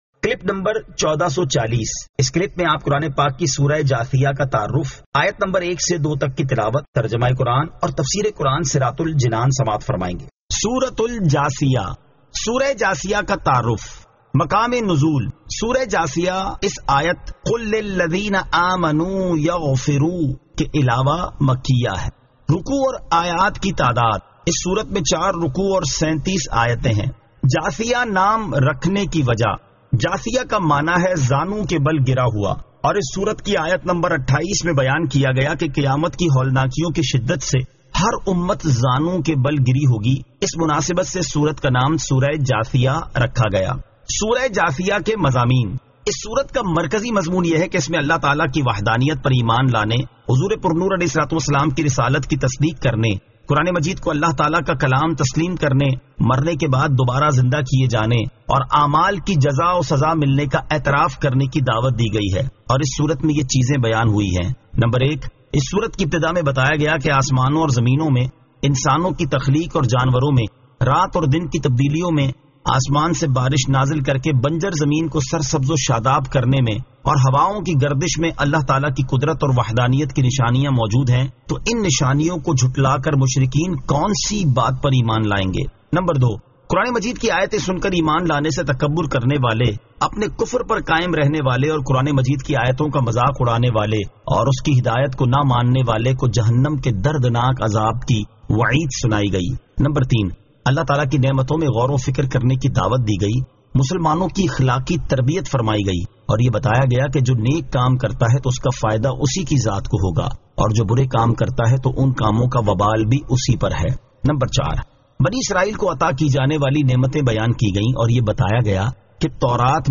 Surah Al-Jathiyah 01 To 02 Tilawat , Tarjama , Tafseer